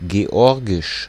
Ääntäminen
Synonyymit georgische Sprache Ääntäminen : IPA: [geˈɔʁgɪʃ] Haettu sana löytyi näillä lähdekielillä: saksa Käännös Erisnimet 1.